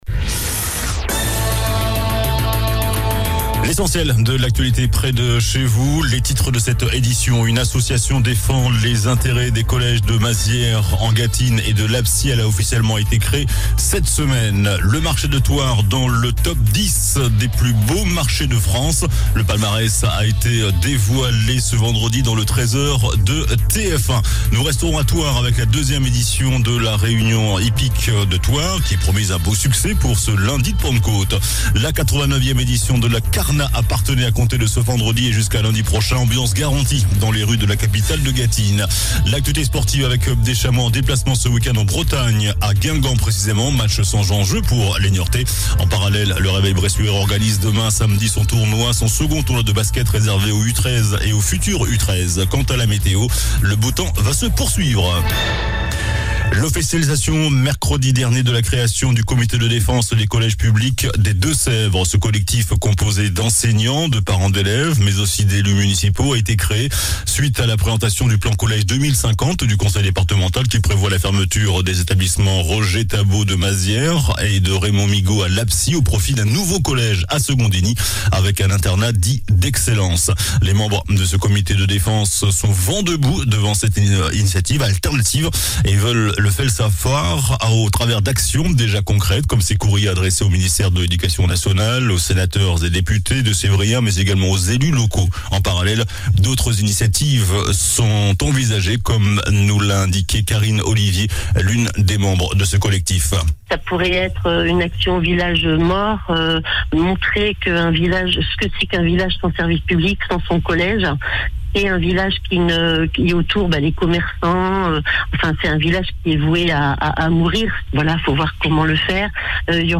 JOURNAL DU VENDREDI 26 MAI ( SOIR )